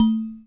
bell_small_muted_01.wav